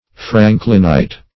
Franklinite \Frank"lin*ite\, n.